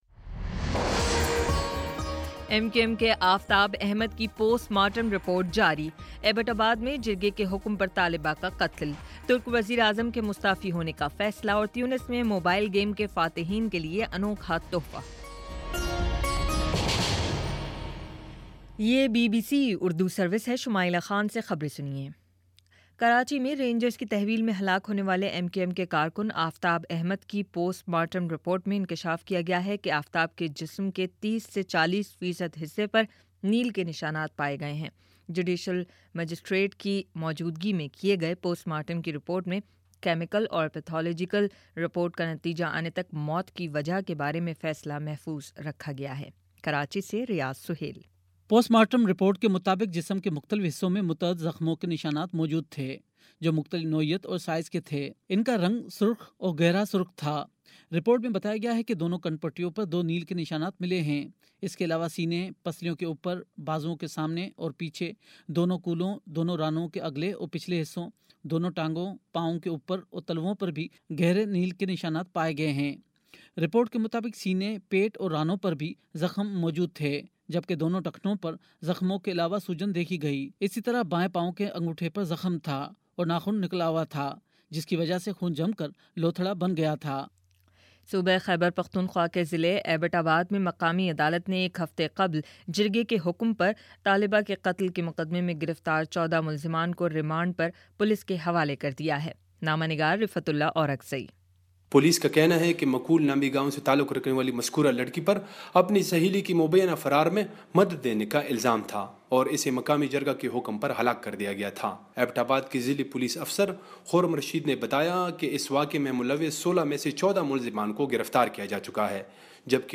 مئی 05 : شام چھ بجے کا نیوز بُلیٹن